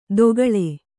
♪ dogaḷe